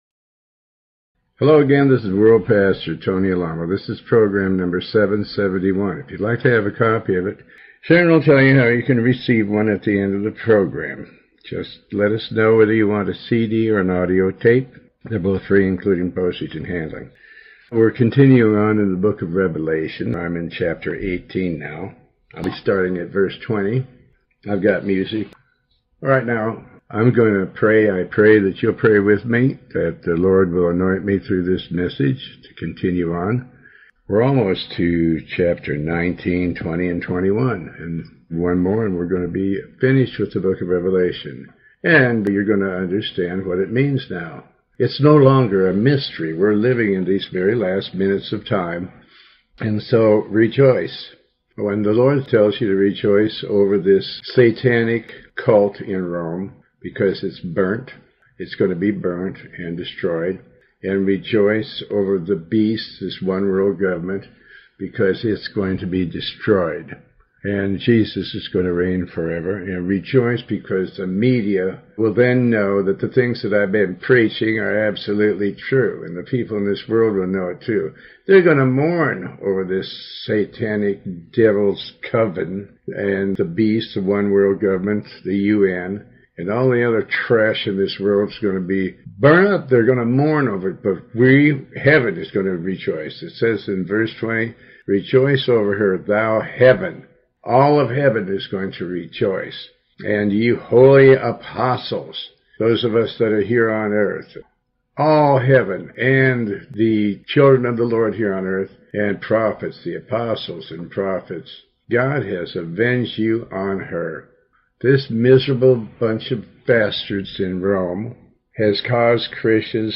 Pastor Tony Alamo reads and comments on the book of revelation Chapter 18